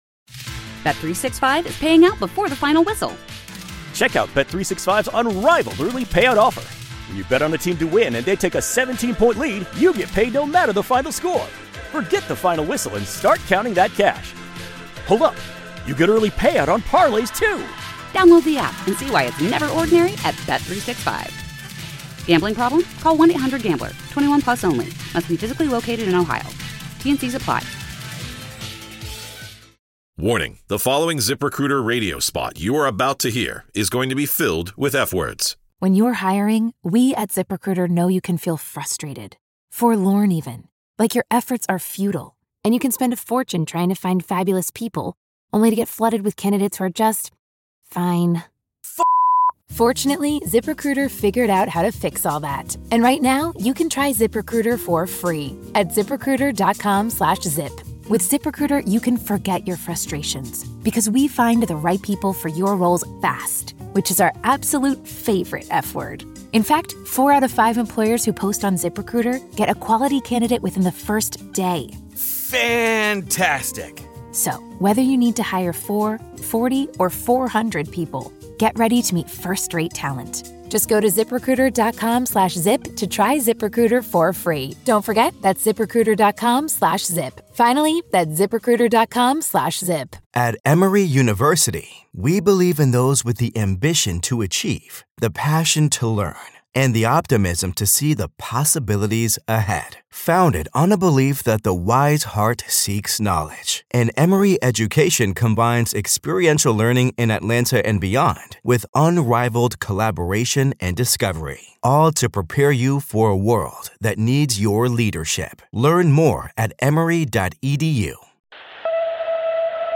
We’ve crafted a dynamic blend of Motivational Speeches from renowned motivational speakers, uplifting messages, and Empowering Words designed to strengthen your Positive Mindset and drive Success. Discover how discipline, a growth mindset, and Mindfulness can transform obstacles into opportunitie